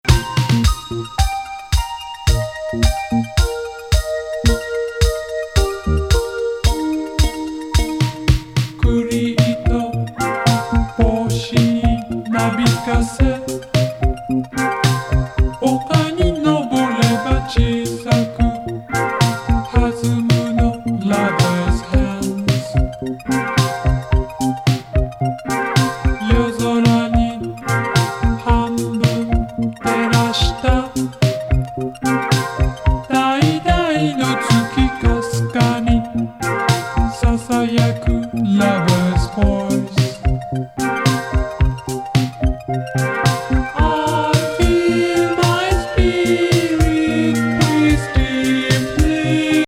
天界コーラス、一転ダーク・ドリーミーNW化も!